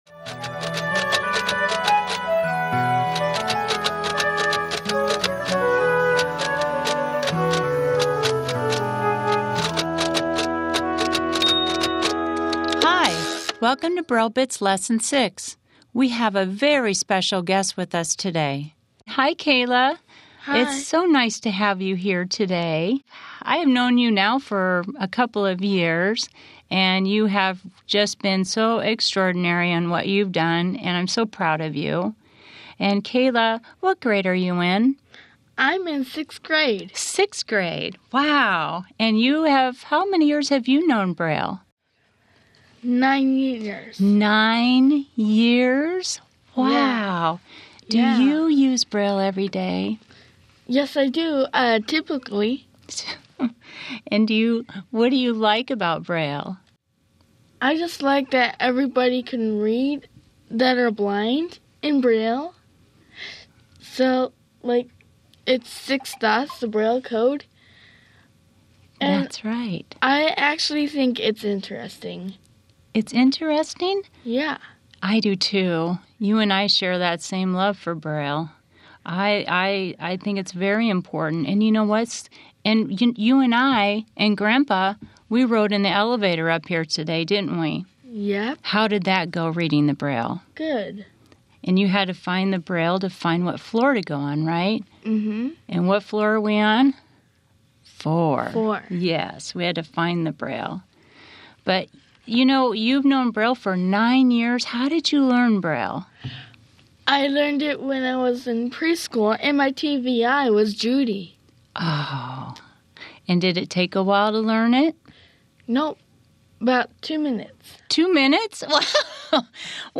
It features each Braille Bits Lesson and an interview with a special guest to discuss all things braille.